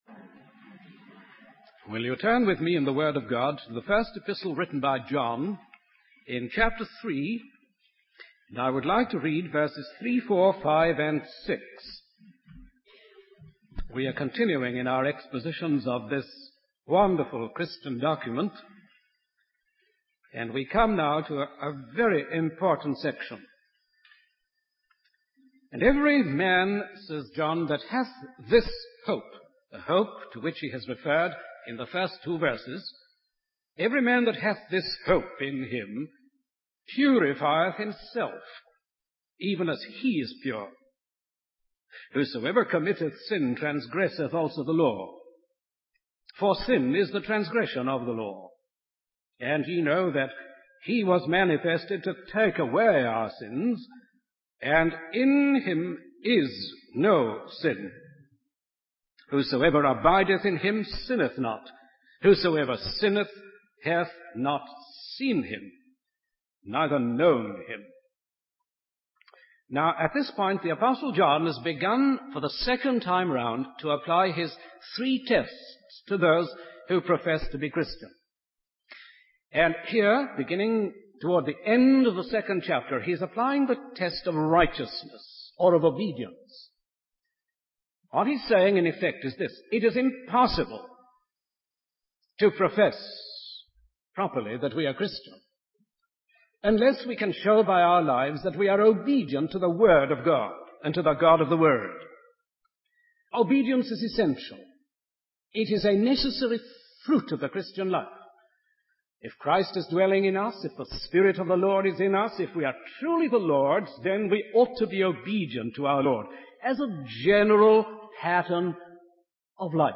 In this sermon, the preacher focuses on two important aspects of the Christian faith: hope and habit. He emphasizes the Christian hope, which is centered around the belief that Jesus Christ will reappear in glory.